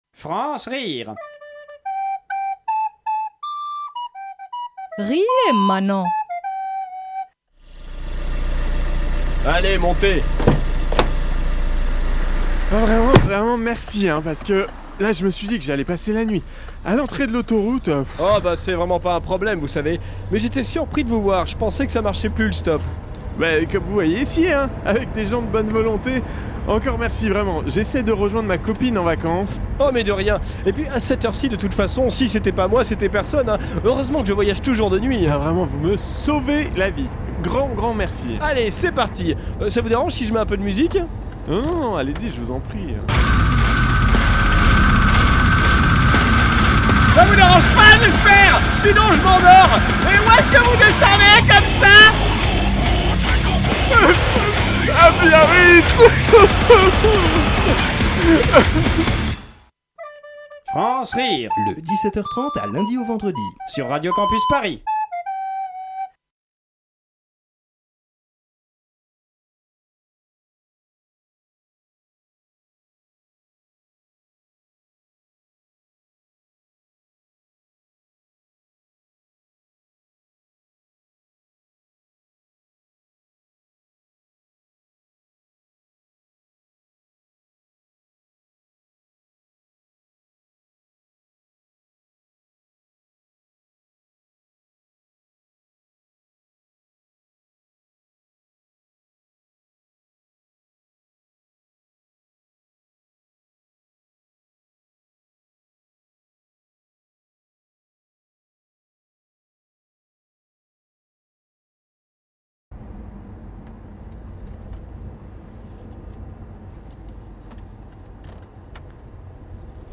Création sonore